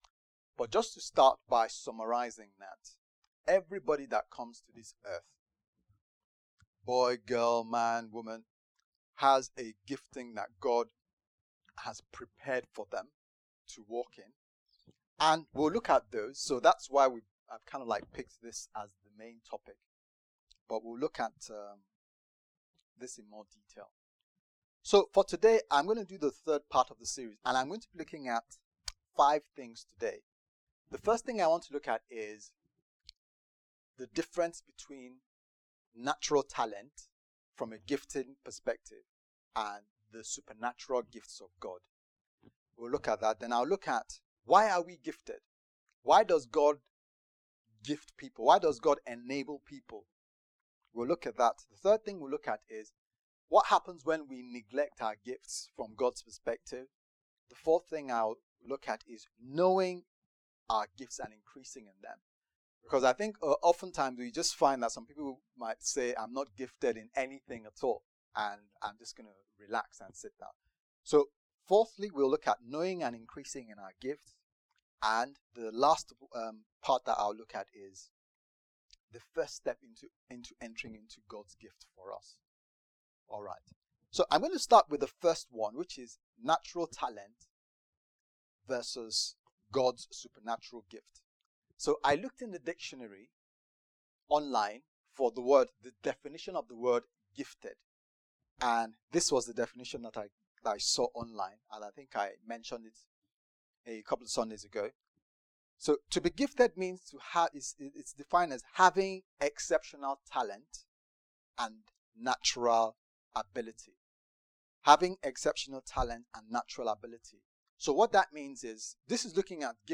Gifted Service Type: Sunday Service « Gifted?